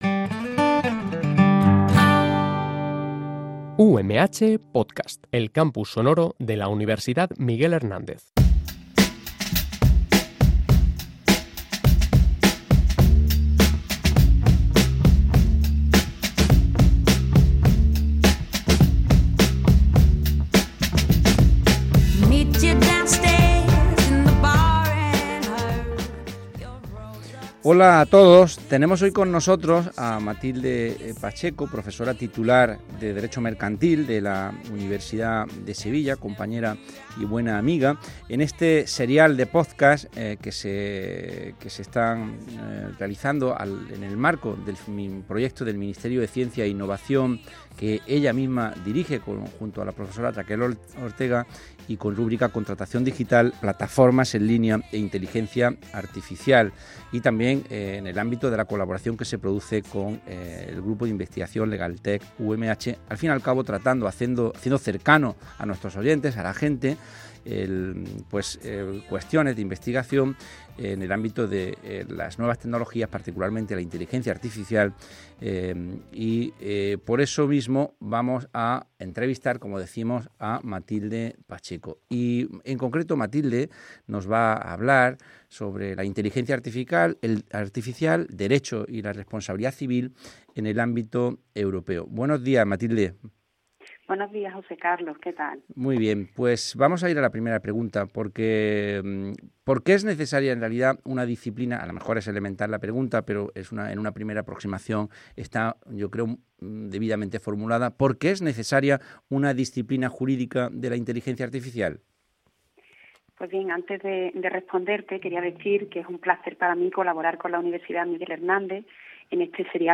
(Español) Entrevista